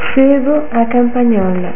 (pronuncia)   pomodori pelati/freschi, melanzane, olio